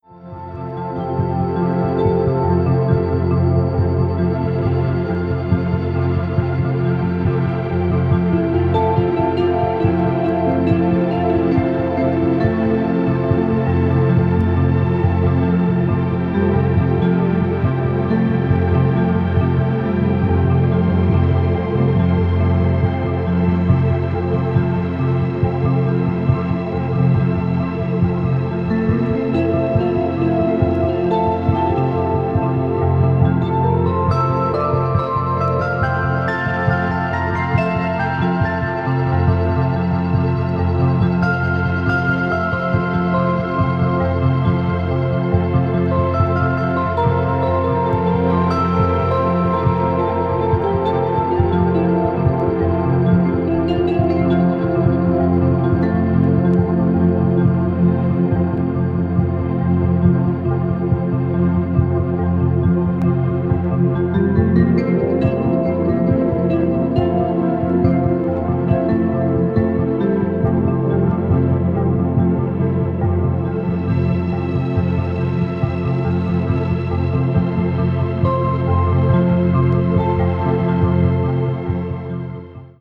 ambient   electronic   experimental   synthesizer